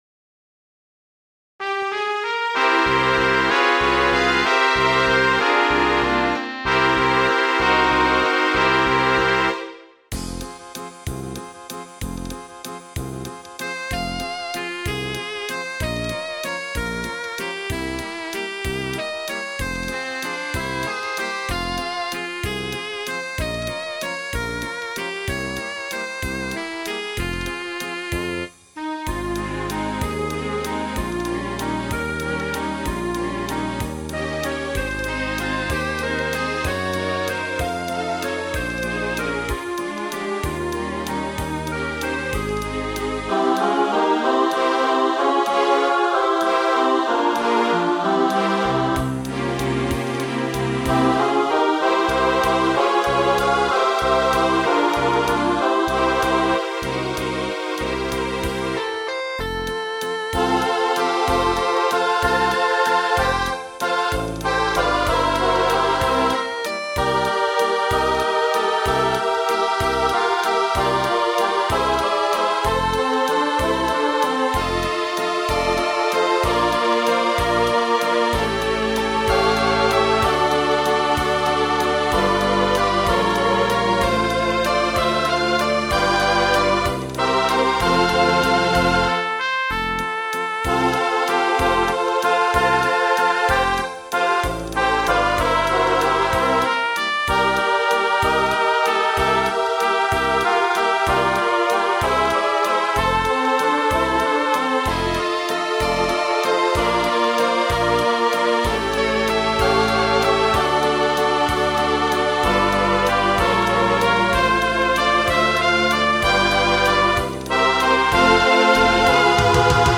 караоке-версия